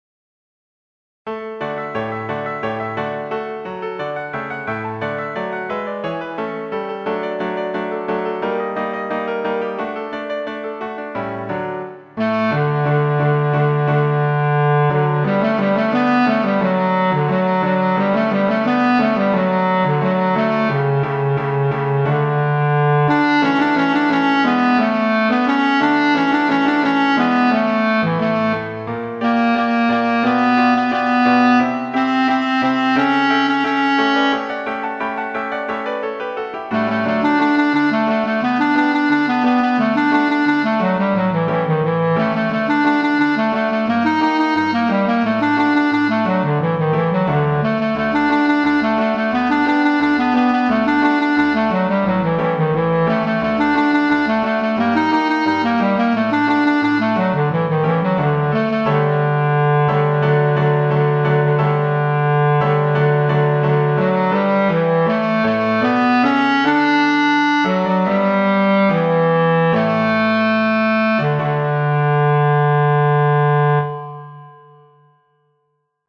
Choral parts are played back using a clarinet sound, while accompaniment and principal parts are played on a piano sound. Constantly full dynamics and mostly steady tempos are used for pitch-drilling purposes.
The part file includes your choral part played prominently, along with accompaniment and principals' vocal lines at lower volume.